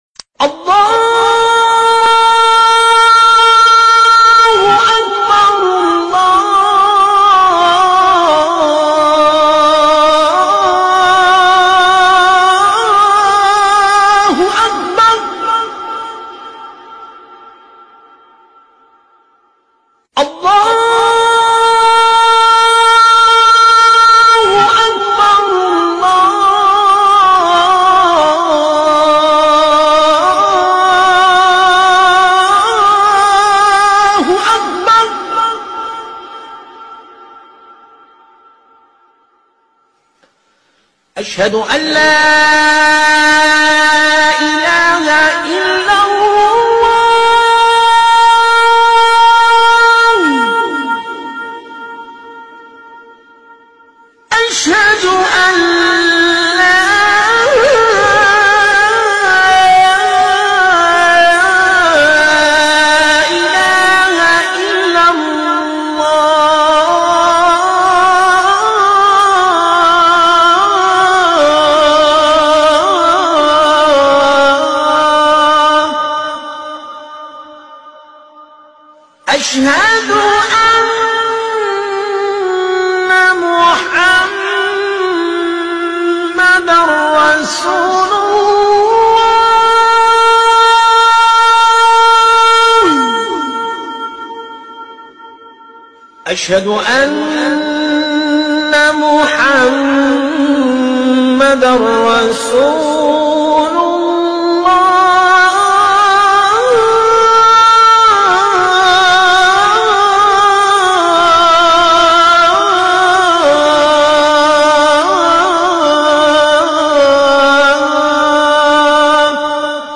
#কিশোরগঞ্জ পাগলা মসজিদের আজান#ভিডিওটা সবাই sound effects free download